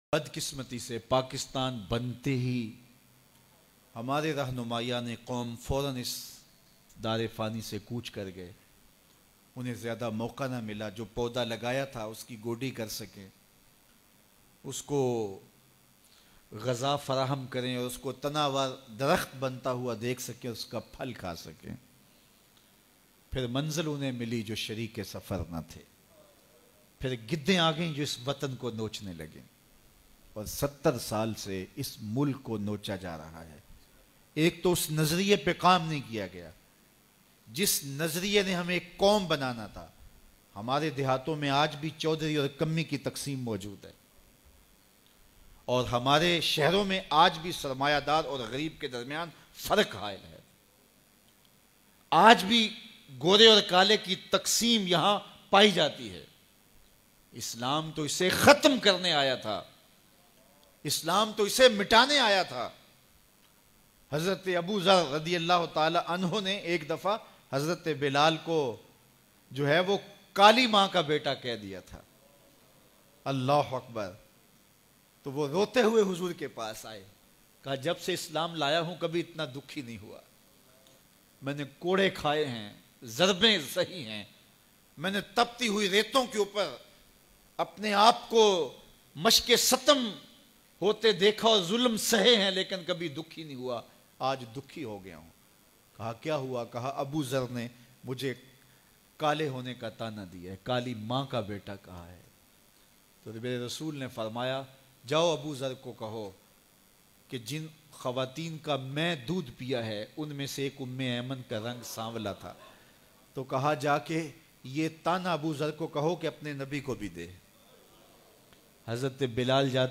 Hazrat BILAL e Habshi ne shikayat kr di Bayan MP3